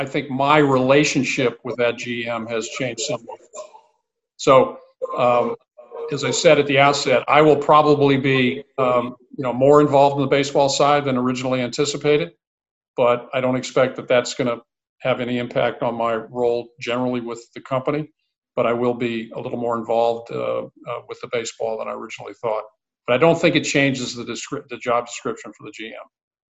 Sandy Alderson made 2 major announcements in his Zoom call with reporters yesterday–one was expected and the other was a bit surprising.